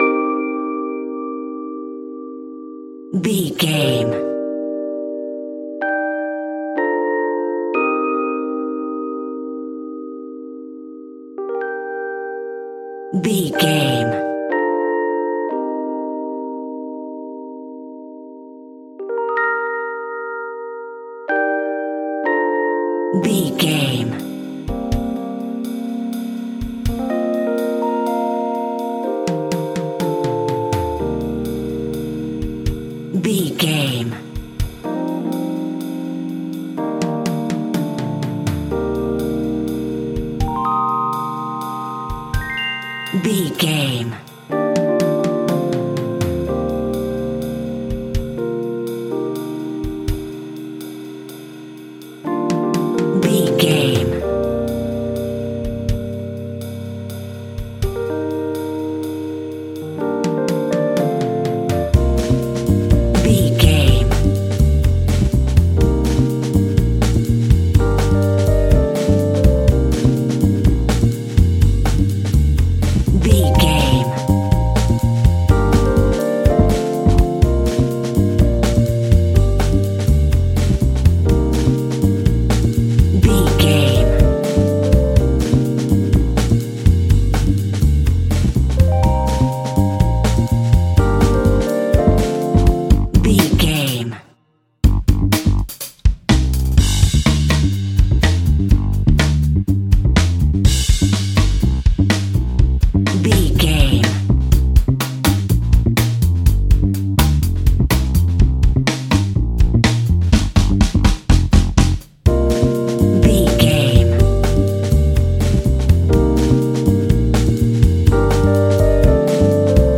Aeolian/Minor
energetic
percussion
electric guitar
acoustic guitar